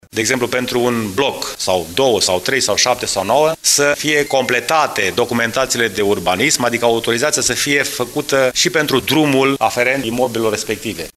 Primarul Brașovului, George Scripcaru, a solicitat Consiliului Local elaborarea unui proiect de hotărâre care să oblige dezvoltatorii imobiliari să reglementeze situația drumurilor de acces încă din momentul în care elaborează planurile urbanistice zonale: